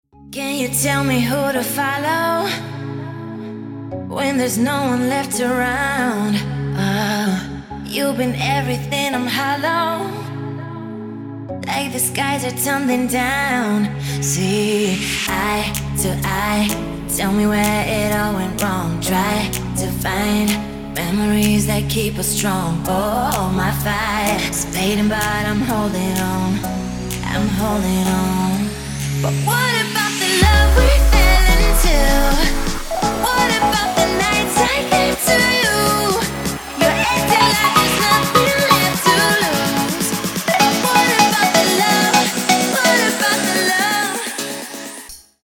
• Качество: 192, Stereo
поп
женский вокал
dance
Melodic
romantic